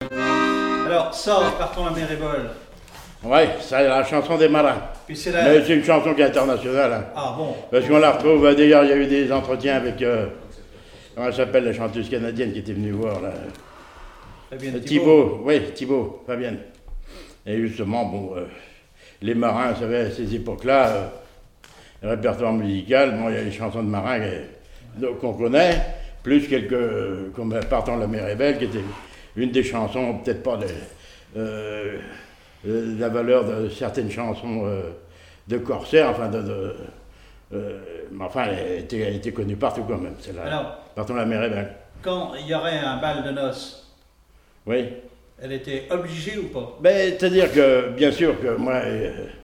chanteur(s), chant, chanson, chansonnette
Témoignage sur la musique et des airs issus du Nouc'h